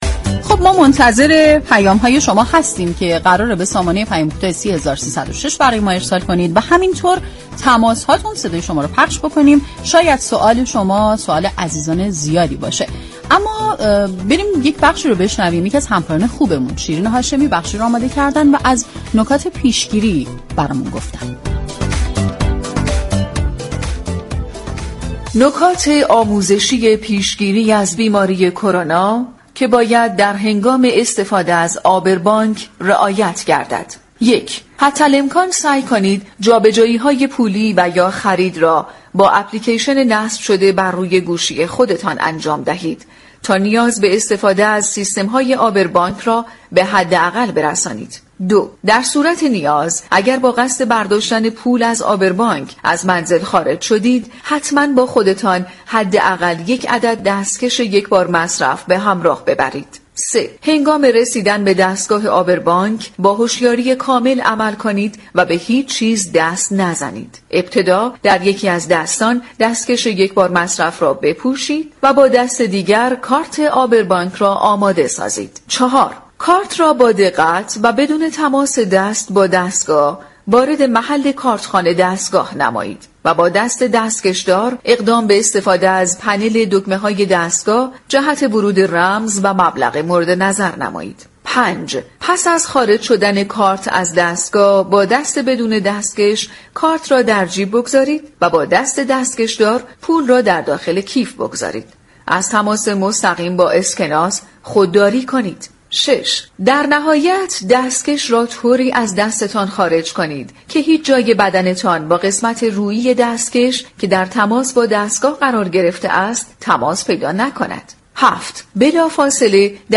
بخشی از برنامه مشاور رادیو ورزش كه شامل صحبت های متخصصان درباره كرونا و پاسخگویی به سوالات عموم است